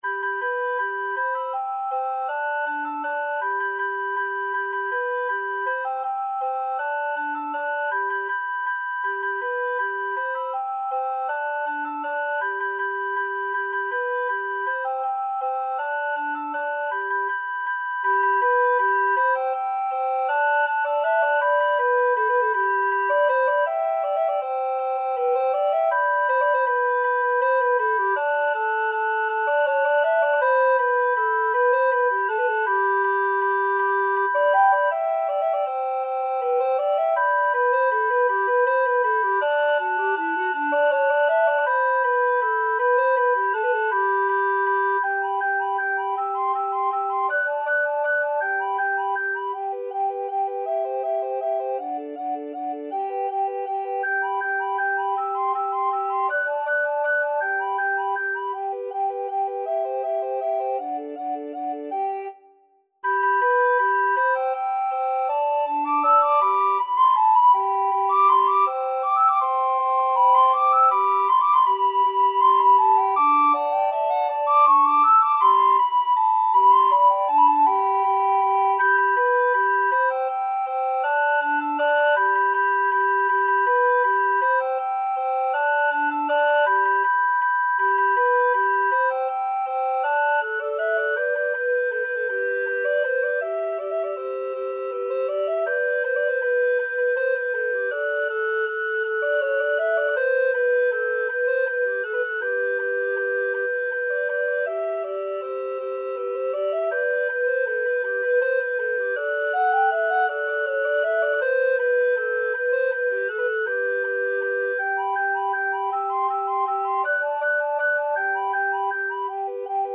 Soprano RecorderAlto RecorderTenor Recorder
Allegro Vivo = 160 (View more music marked Allegro)
Recorder Trio  (View more Intermediate Recorder Trio Music)
Classical (View more Classical Recorder Trio Music)